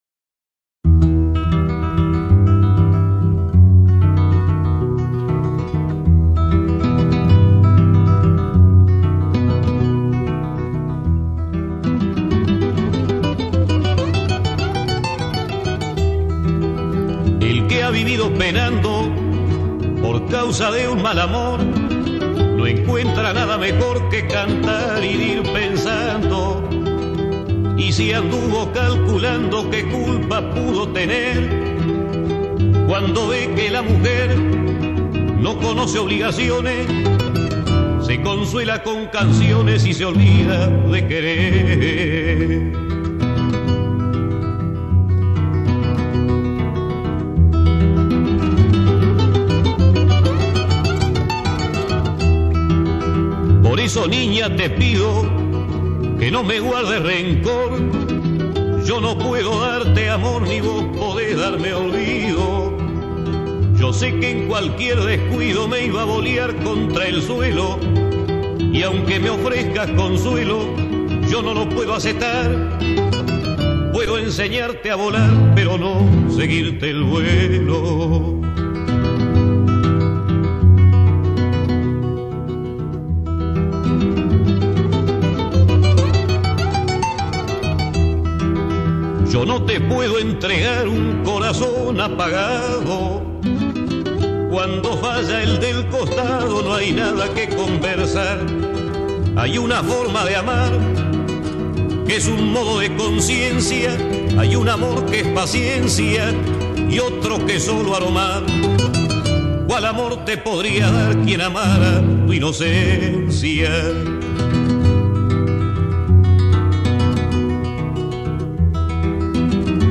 Carpeta: Folklore mp3